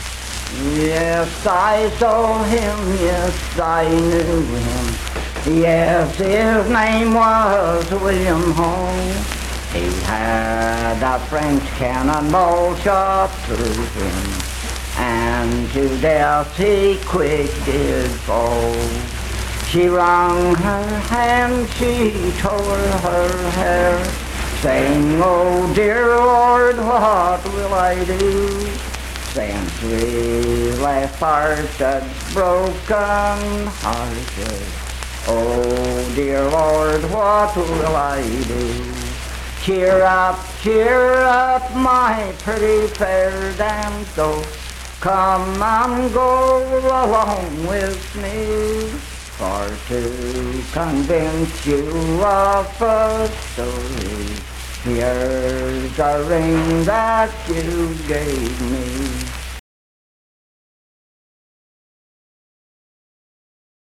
Unaccompanied vocal music performance
Voice (sung)
Roane County (W. Va.), Spencer (W. Va.)